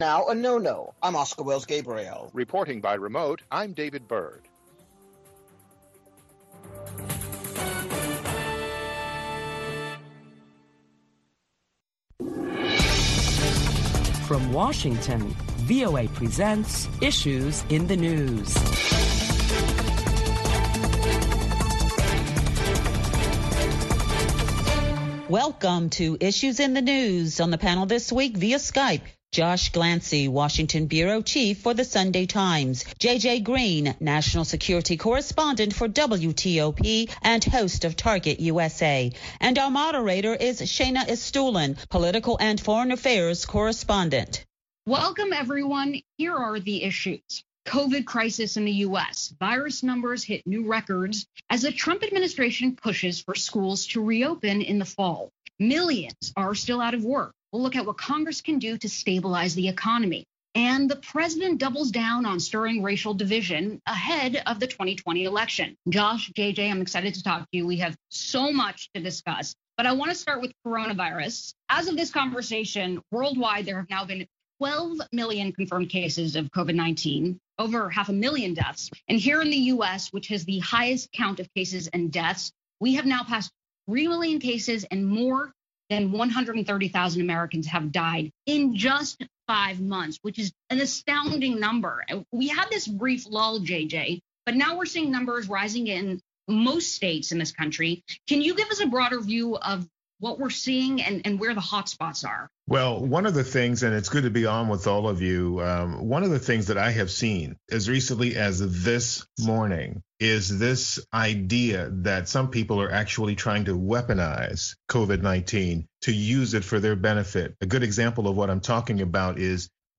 Listen to a panel of prominent Washington journalists as they deliberate the latest top stories of the week including The Trump administration pushes for schools to reopen for what it says is the "holistic health" of the nation.